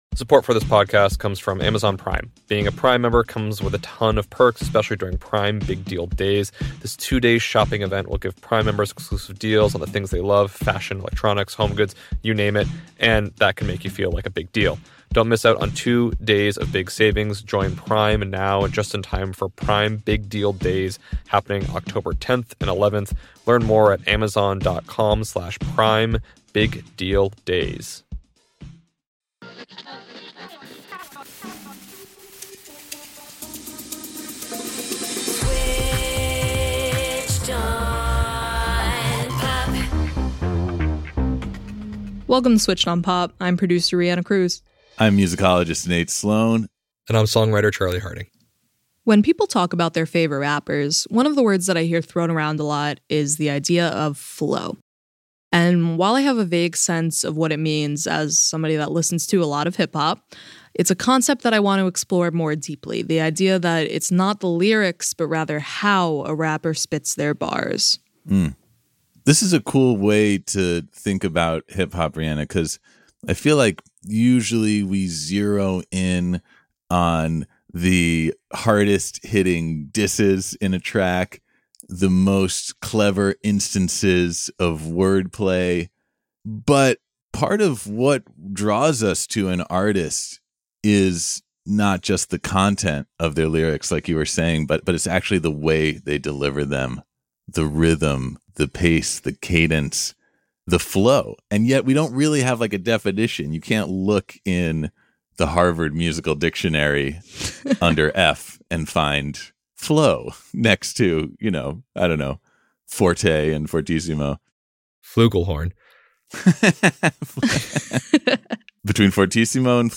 In this episode of Switched On Pop, we interview genre icon DJ Jazzy Jeff on the concept of flow: what it is, how it applies to all music – not just hip-hop – and how any rapper’s flow can be analyzed under his guidelines.